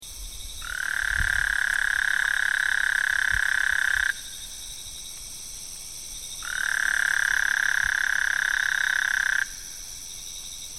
Call is short trill, repeated frequently, large choruses can be quite deafening - somewhat akin to a pneumatic drill !